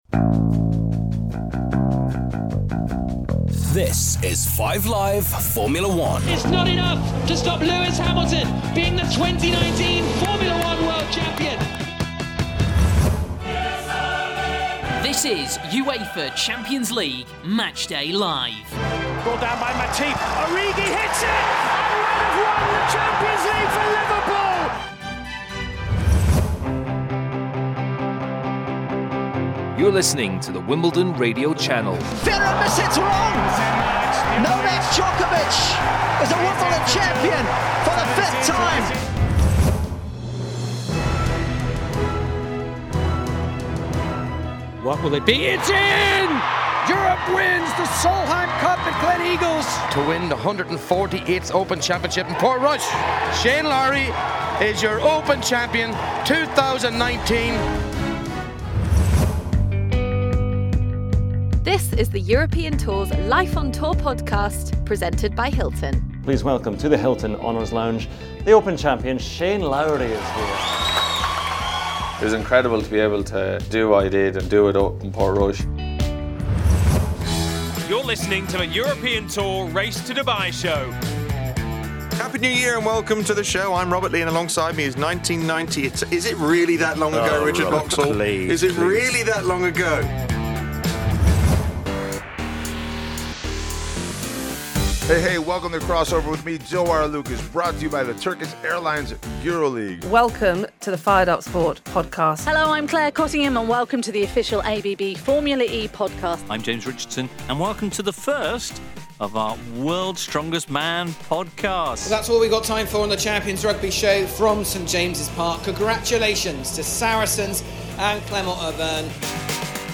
A sample of what IMG can produce for you - from Live coverage at some of the biggest events on the planet, to fun and considered Podcasts giving listeners unique insight to the world of sport.